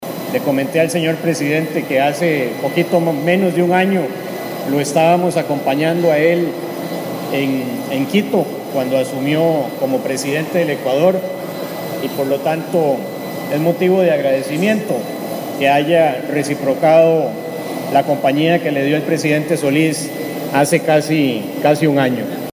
En dicho traspaso participó el Presidente Luis Guillermo Solís, acto que recordó el Canciller González al recibirlo en el aeropuerto:
AUDIO-CANCILLER-ECUADOR.mp3